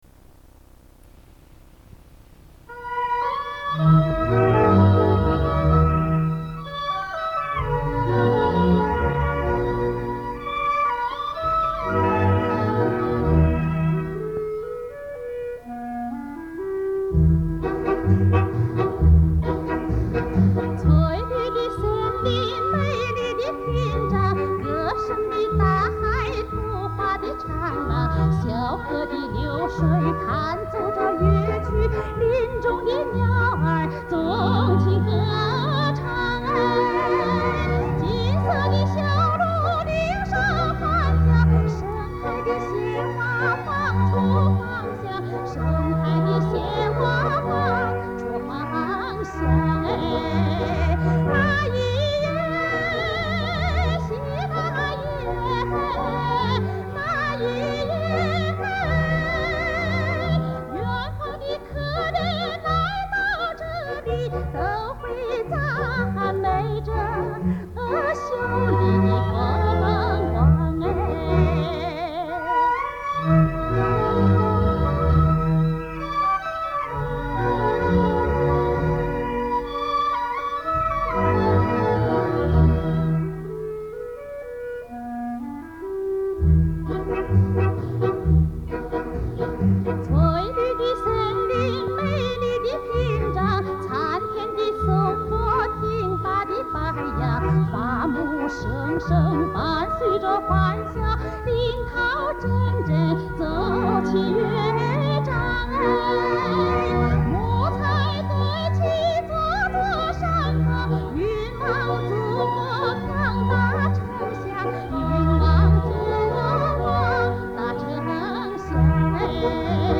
注：磁带音质一般。